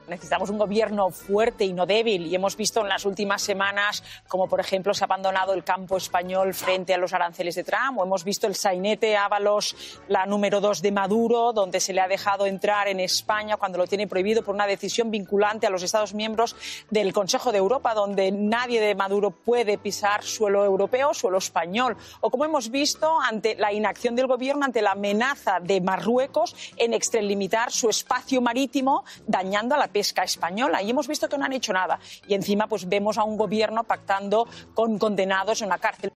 Palabras de Dolors Montserrat